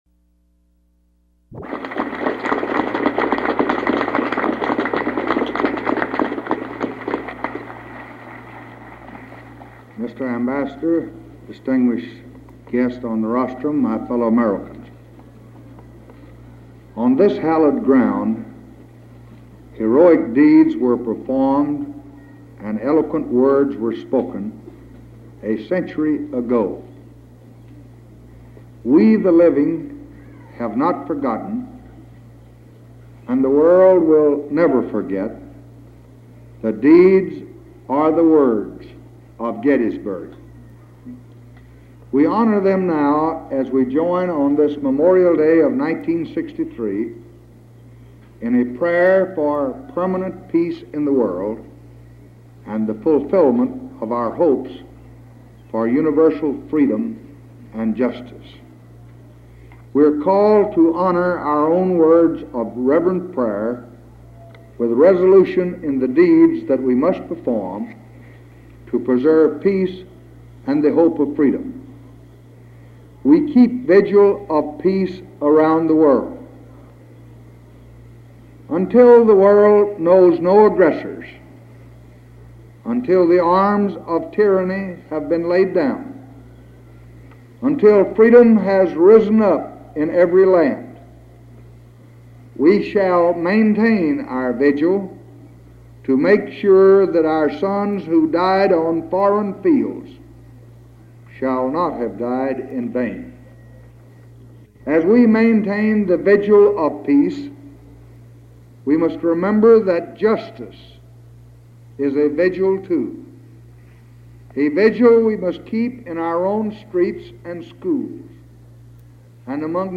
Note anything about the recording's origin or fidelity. May 30, 1963: Remarks at Gettysburg on Civil Rights | Miller Center